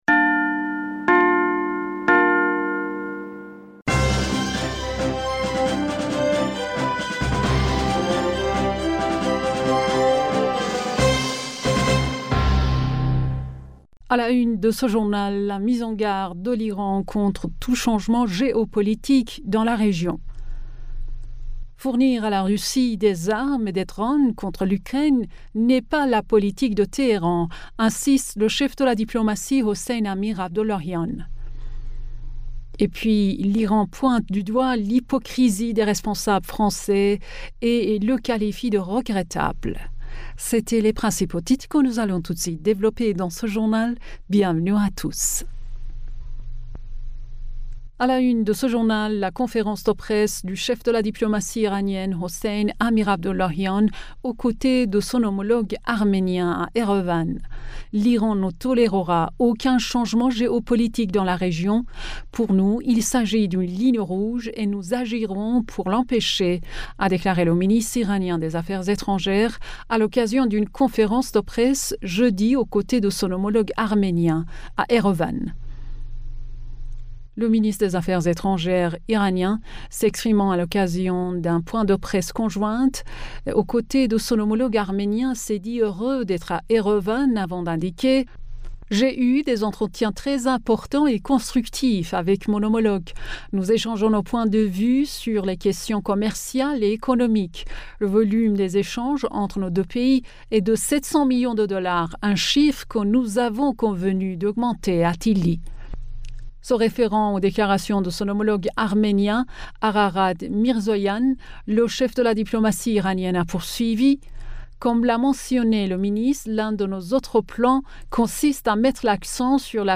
Bulletin d'information Du 21 Octobre